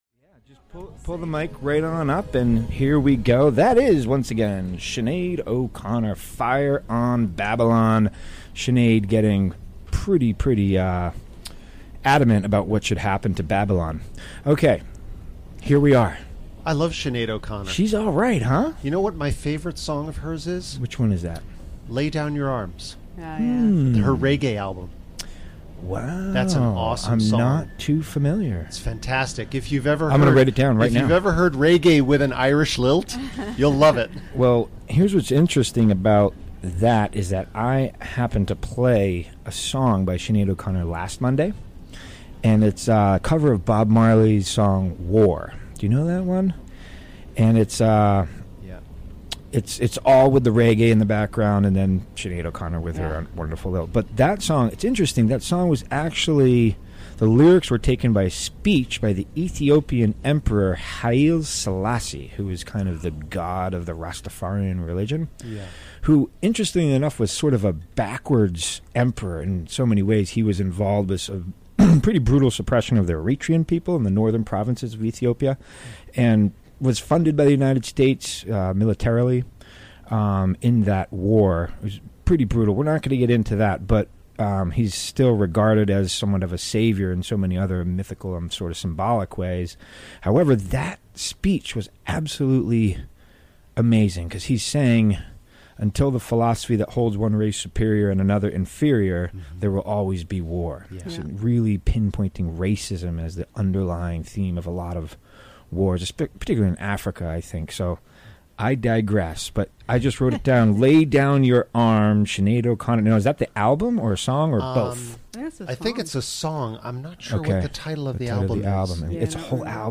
Recorded live on the WGXC Afternoon show on March 12, 2018.